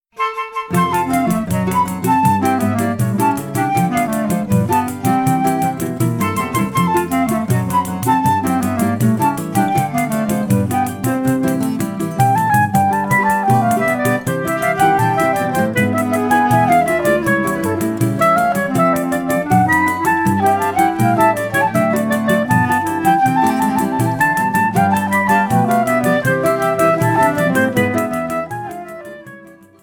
clarinet
Choro ensemble in the other songs